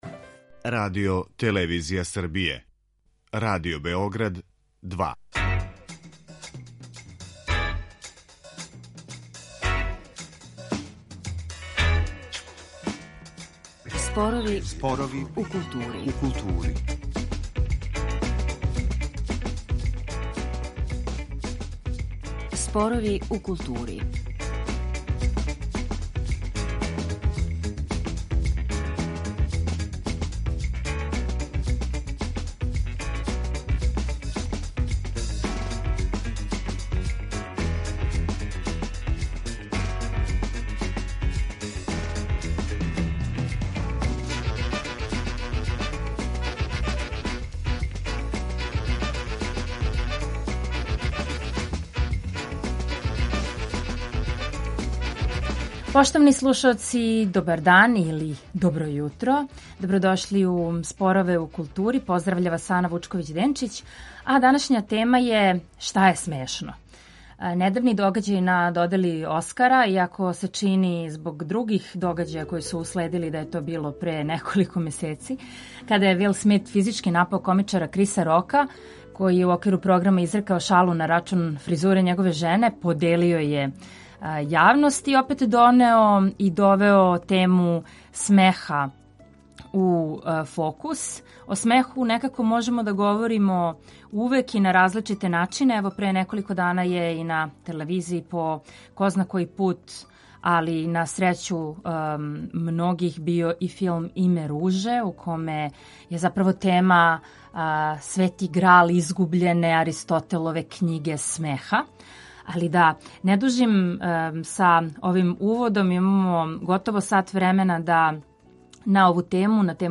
О овим темама у данашњим Споровима у култури говоре клинички психолог и психотерапеут